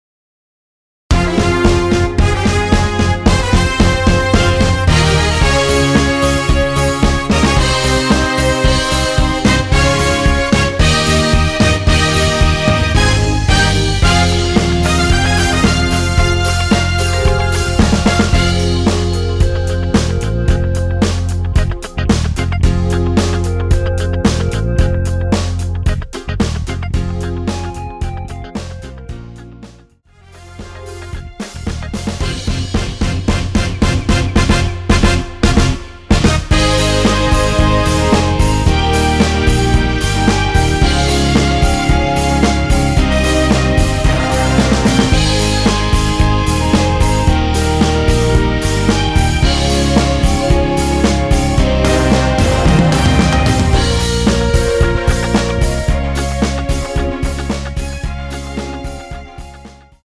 원키에서(-2)내린 멜로디 포함된 MR입니다.(미리듣기 확인)
Fm
앞부분30초, 뒷부분30초씩 편집해서 올려 드리고 있습니다.
중간에 음이 끈어지고 다시 나오는 이유는